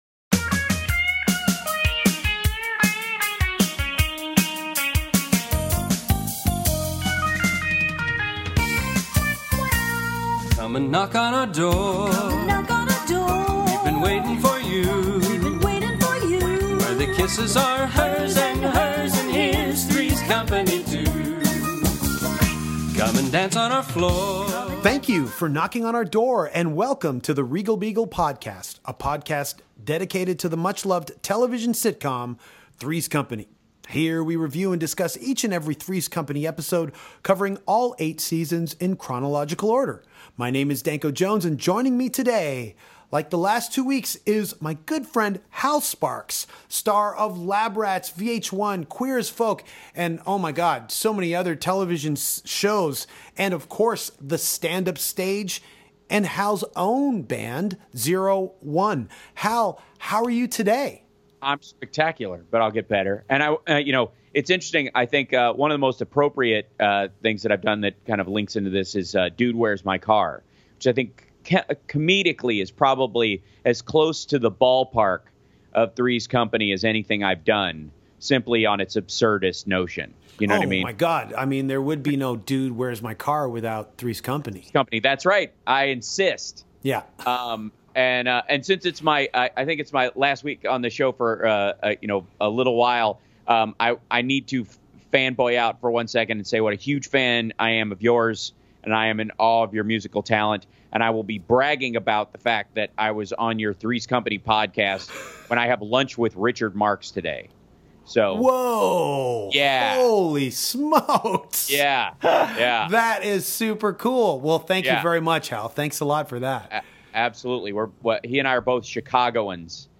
co-host is Hal Sparks (Queer As Folk, Lab Rats, Talk Soup)